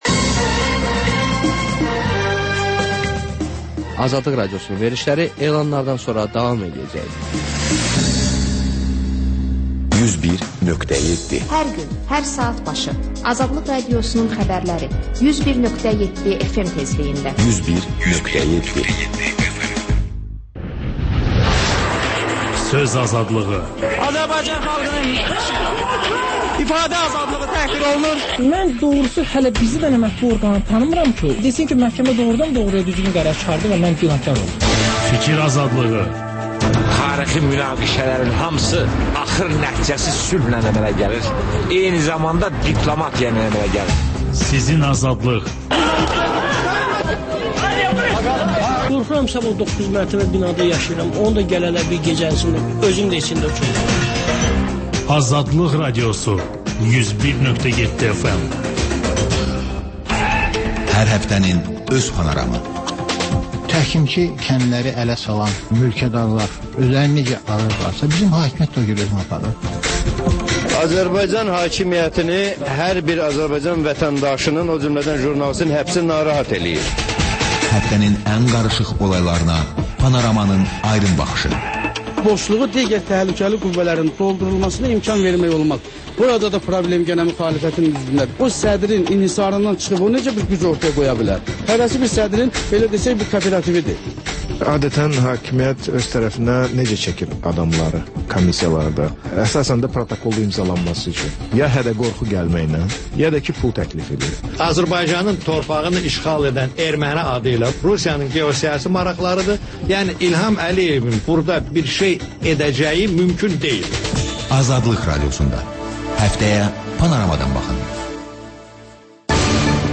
Xəbərlər, HƏMYERLİ: Xaricdə yaşayan azərbaycanlılar haqda veriliş, sonda MÜXBİR SAATI: Müxbirlərimizin həftə ərzində hazırladıqları ən yaxşı reportajlardan ibarət paket